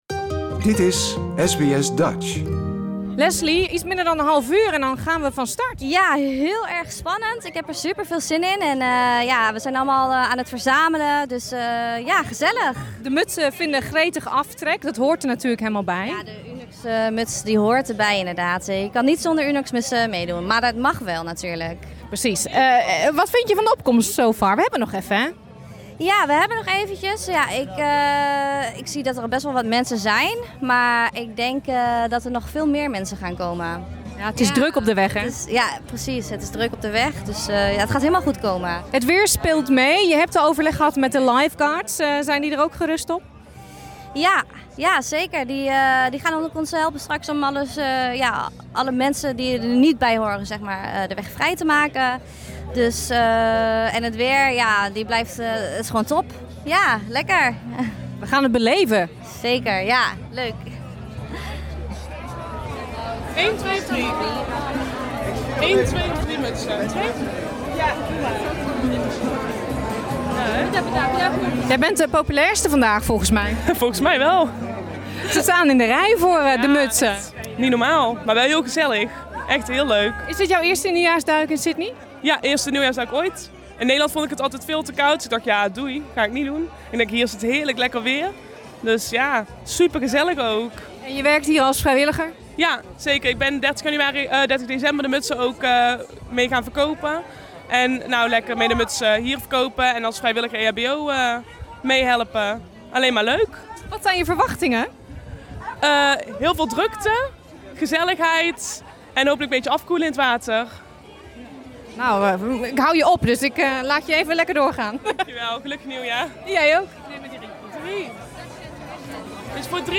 In Perth, Melbourne en Sydney vonden gisteren nieuwjaarsduiken plaats. Wij waren erbij op Bondi Beach toen zo'n 900 mensen, de meeste met oranje Unox muts op hun hoofd, de zee inrenden om het nieuwe jaar te vieren.